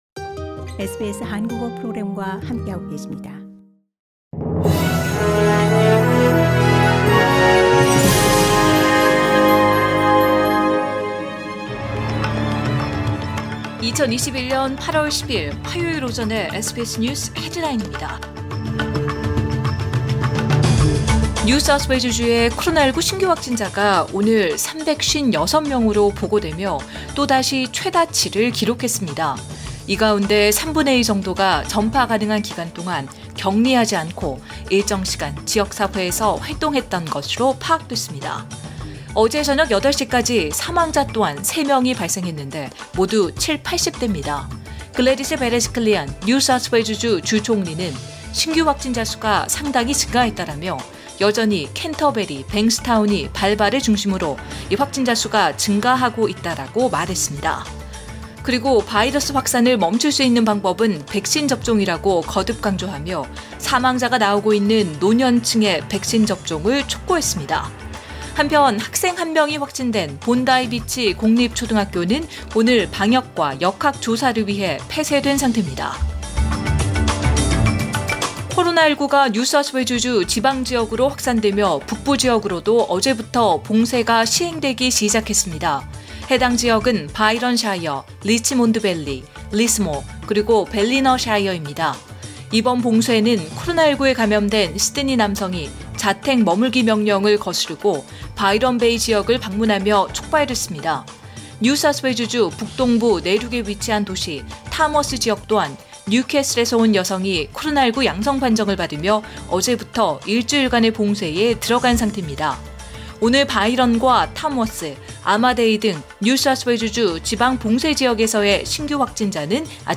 2021년 8월 10일 화요일 오전의 SBS 뉴스 헤드라인입니다.